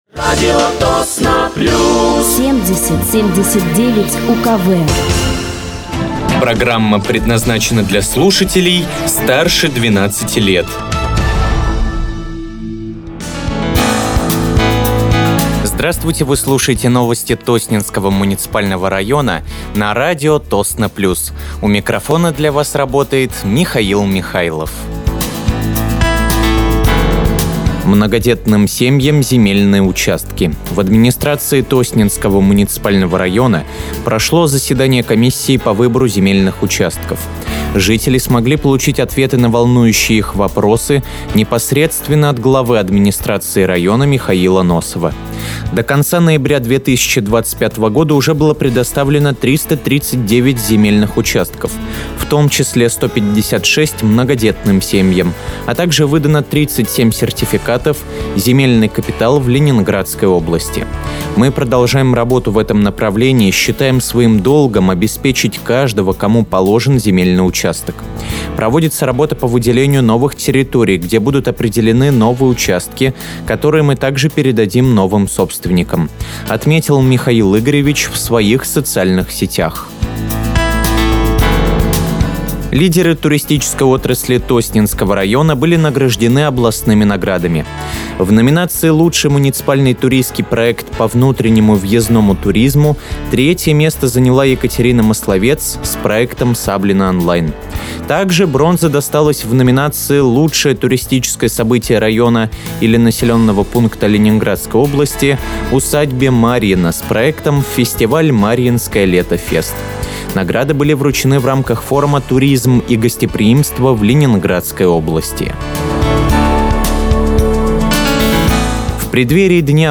Выпуск новостей Тосненского муниципального района от 01.12.2025
Вы слушаете новости Тосненского муниципального района на радиоканале «Радио Тосно плюс».